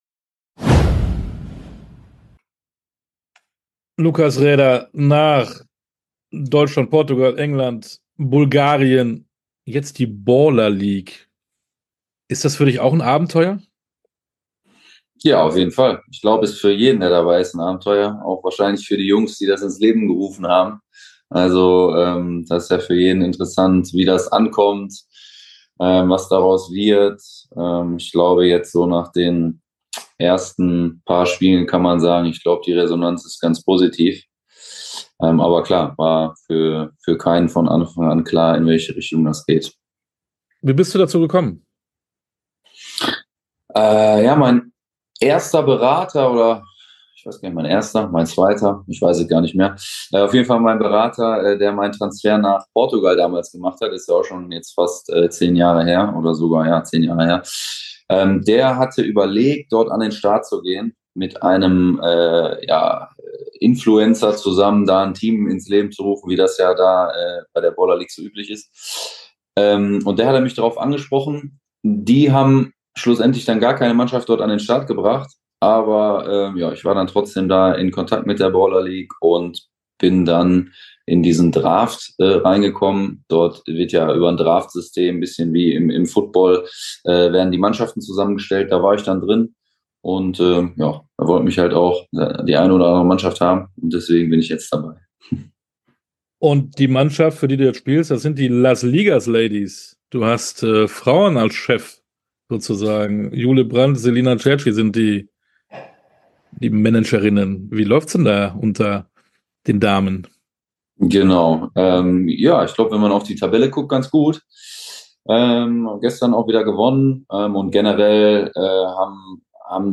Fussball Torwart in der Baller League ~ Sportstunde - Interviews in voller Länge Podcast